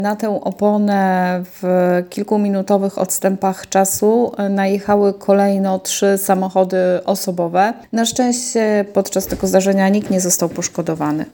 Mówiła Radiu 5